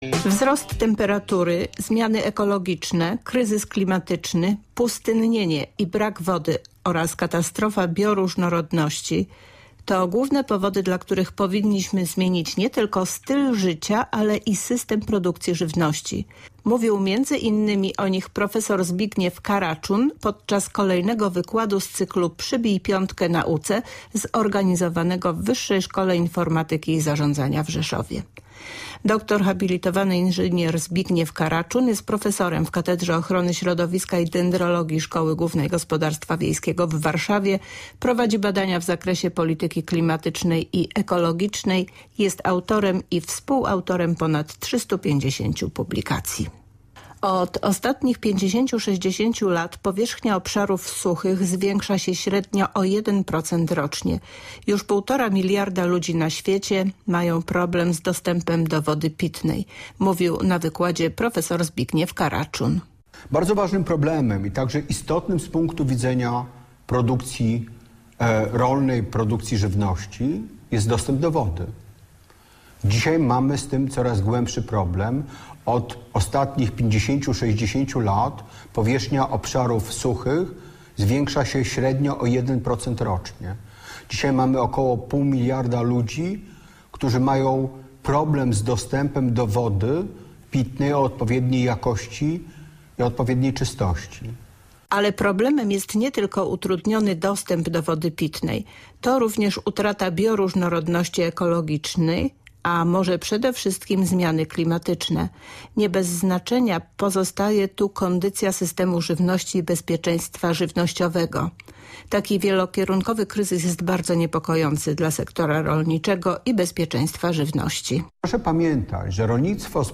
Cykl zorganizowano w Wyższej Szkole Informatyki i Zarzadzania w Rzeszowie.